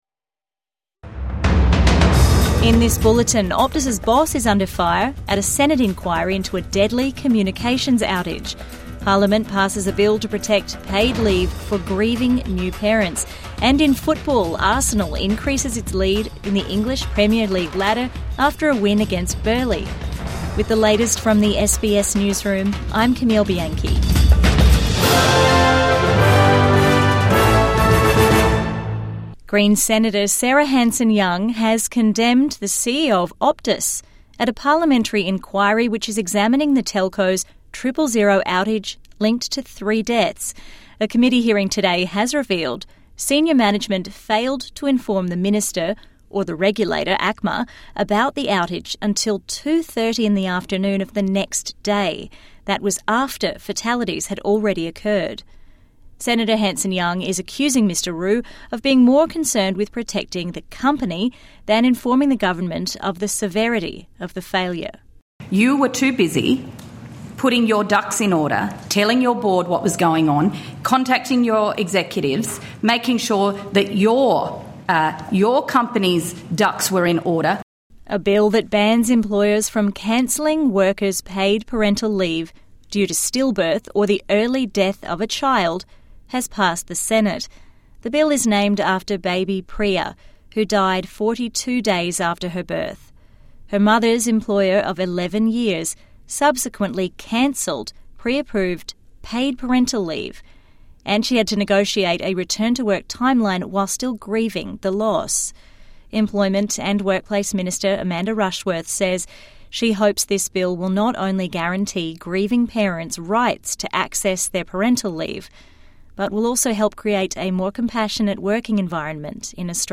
Optus CEO berated at Parliamentary Inquiry | Evening News Bulletin 3 November 2025